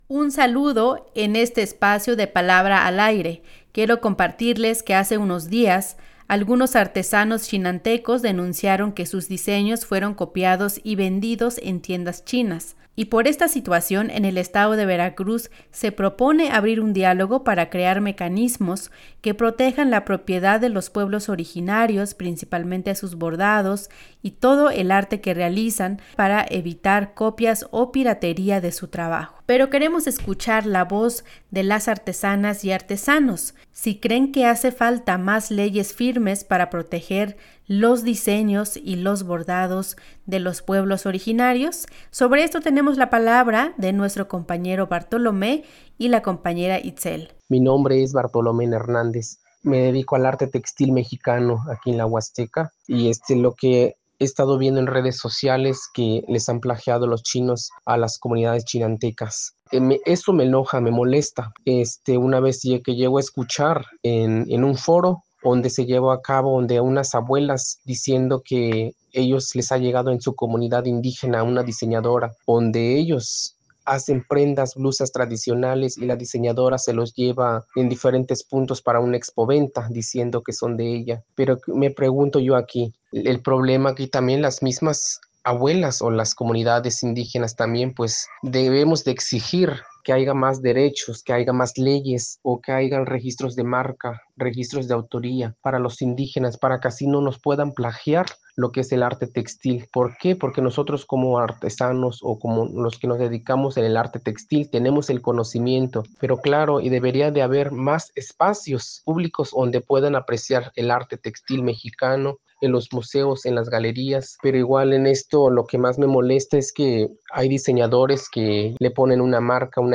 Sobre este tema tenemos la palabra de un artesano y una artesana de esta región de la huasteca Veracruzana.